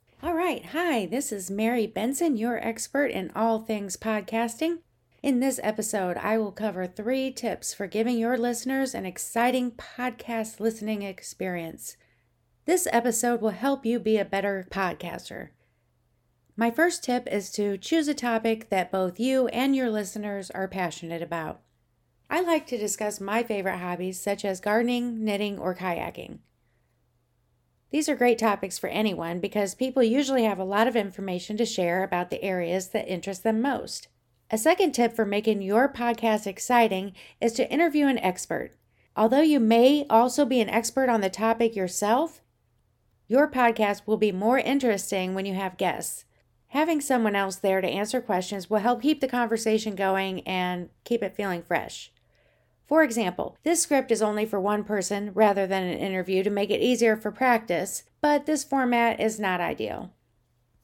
example audio recording of a podcast with an edited audio track for an example of how your podcast should sound when you're finished.